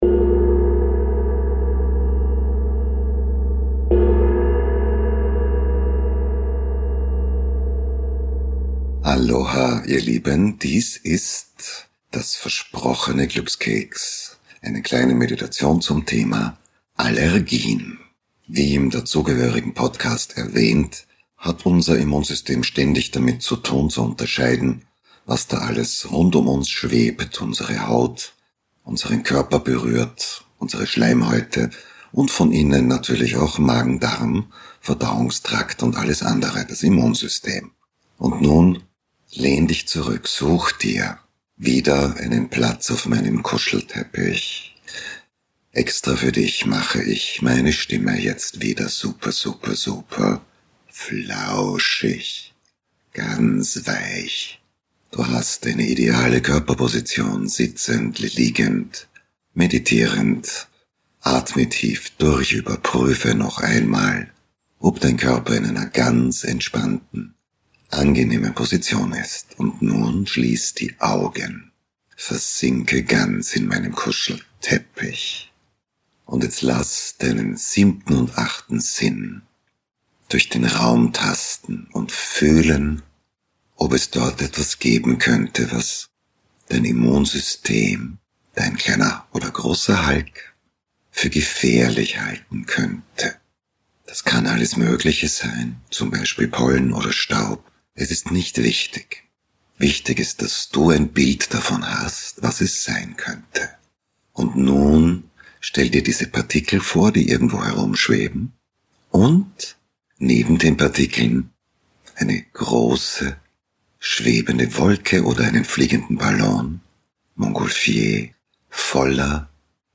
Von Hatschi zu Ahhh – die Meditation, die dein Immunsystem besänftigt.